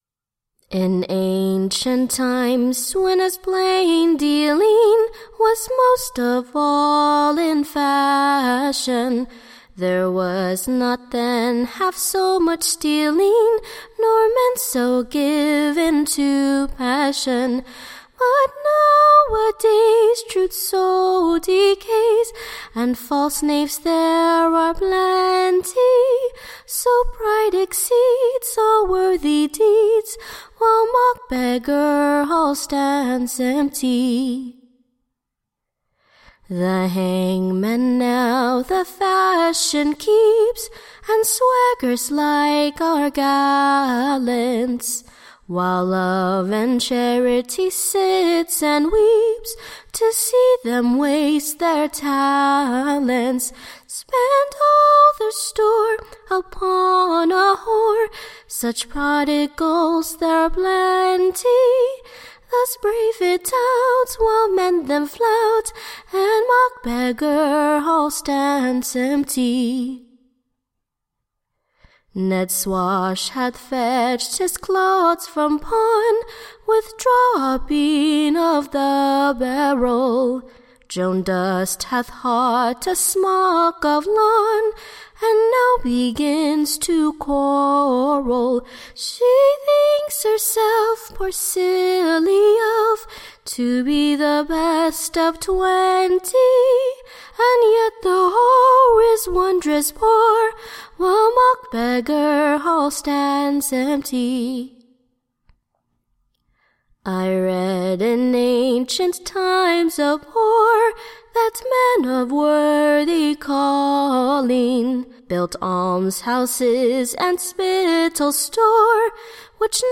Second “Mock-Beggar Hall” ballad
” lamentably